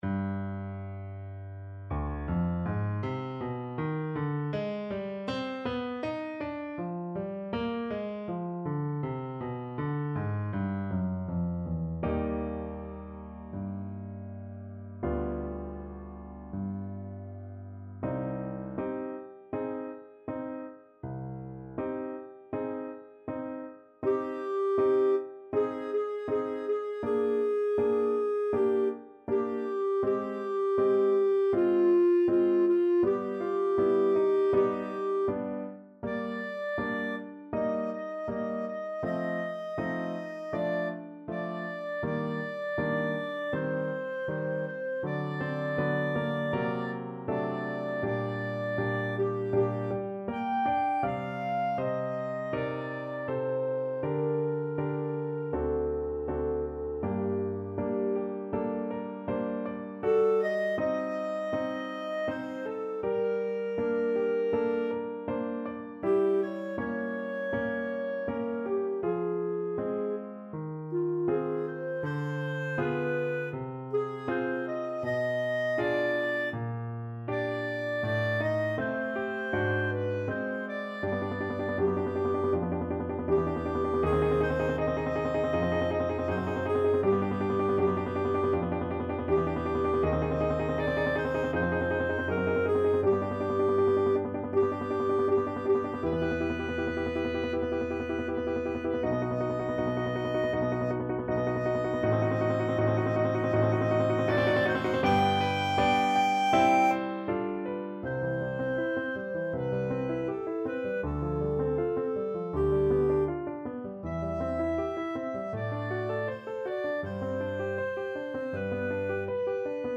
Clarinet
C minor (Sounding Pitch) D minor (Clarinet in Bb) (View more C minor Music for Clarinet )
~ = 100 Molto moderato =80
Classical (View more Classical Clarinet Music)